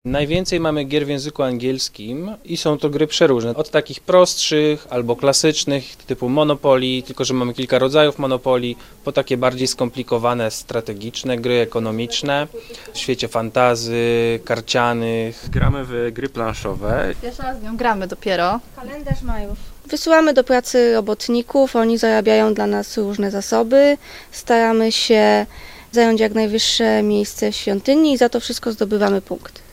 Nazwa Plik Autor Mówią uczestnicy Dnia Gier Planszowych audio (m4a) audio (oga) Dzień Gier Planszowych w Bibliotece Uniwersytetu Łódzkiego odbywa się w każdą pierwszą sobotę miesiąca.